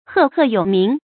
注音：ㄏㄜˋ ㄏㄜˋ ㄧㄡˇ ㄇㄧㄥˊ
赫赫有名的讀法